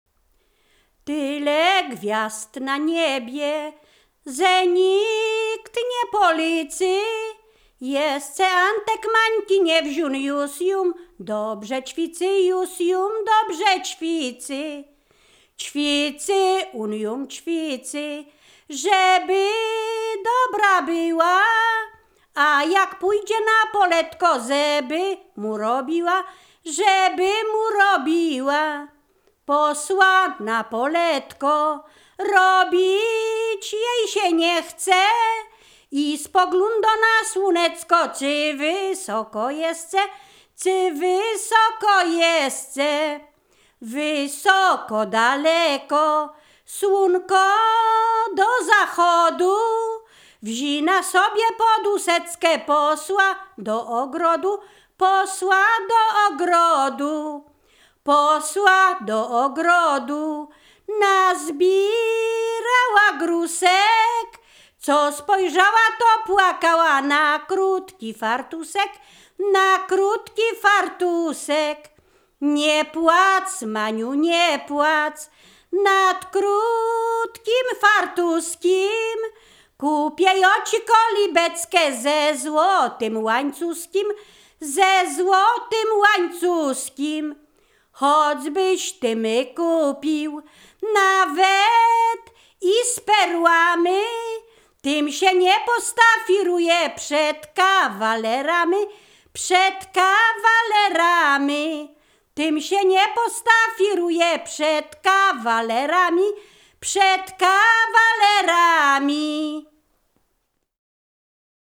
Ziemia Radomska
województwo mazowieckie, powiat przysuski, gmina Rusinów, wieś Brogowa
liryczne miłosne weselne